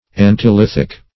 Search Result for " antilithic" : The Collaborative International Dictionary of English v.0.48: Antilithic \An`ti*lith"ic\, a. (Med.)